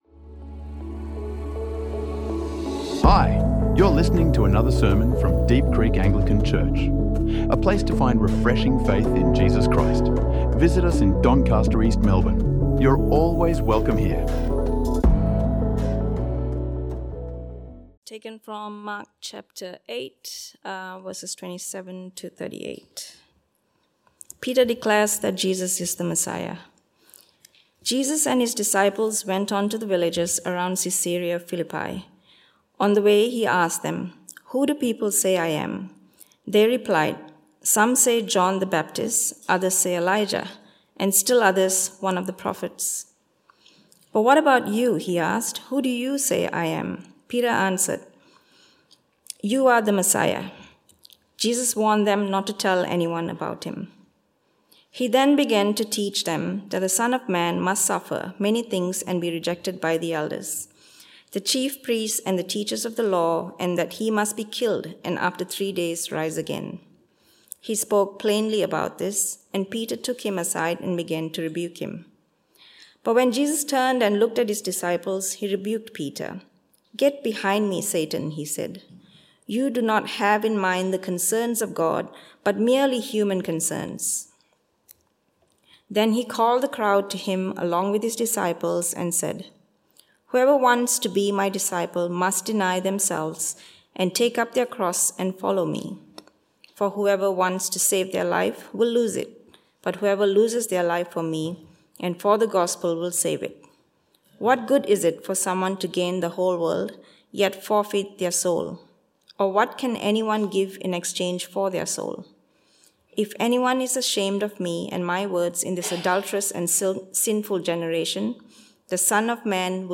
| Sermons | Deep Creek Anglican Church